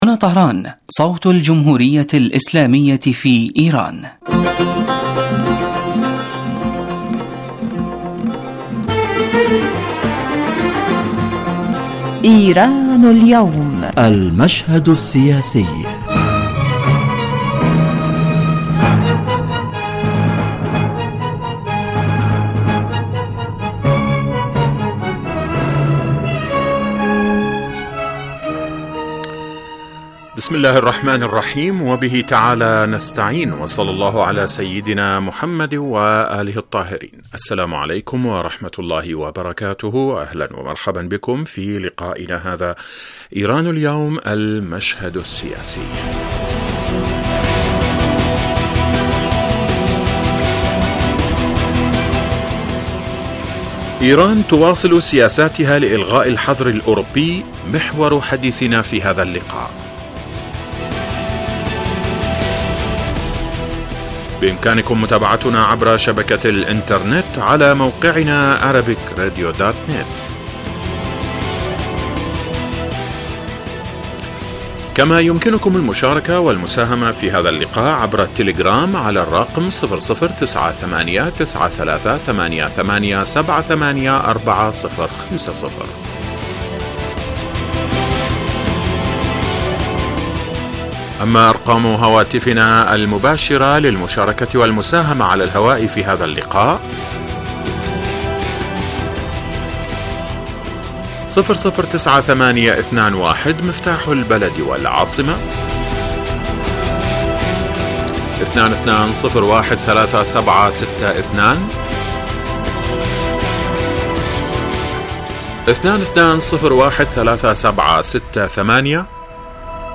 يتناول هذا البرنامج كما هو واضح من تسميته آخر القضايا والأحداث الإيرانية ويختص كل أسبوع بموضوع من أهم موضوعات الساعة في ايران وتأثيره على الساحة الإقليمية ويتطرق إليه ضيف البرنامج في الاستوديو كما يطرح نفس الموضوع للمناقشة وتبادل النظر على خبير آخر يتم استقباله على الهاتف